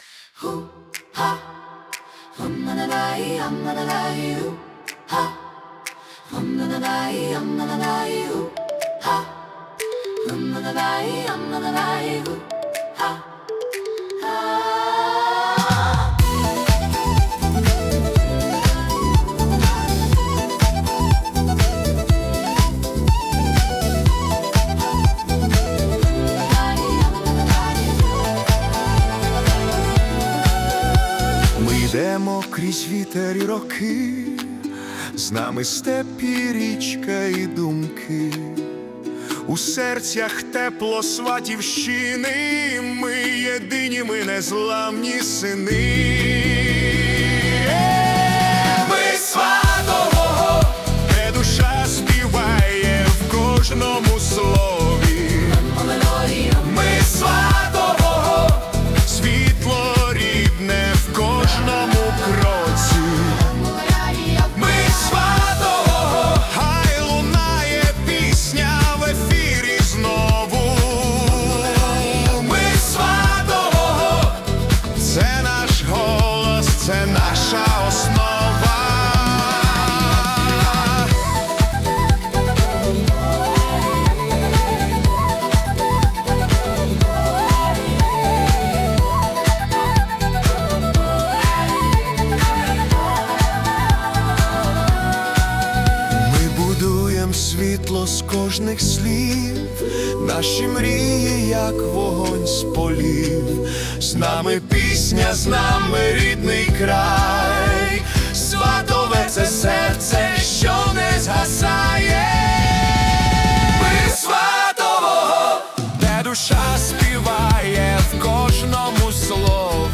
Nu Disco / Electro Folk Fusion